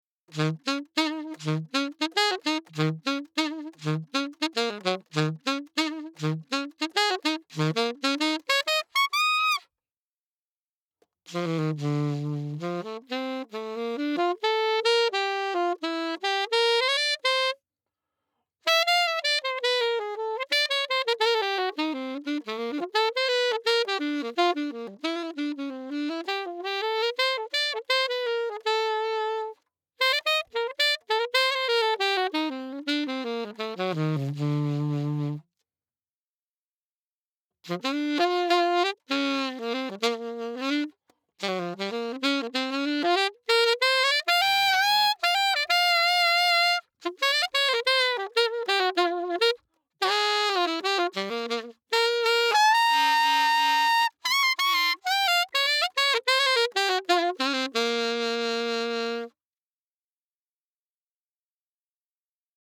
Saxophone “acapella” samples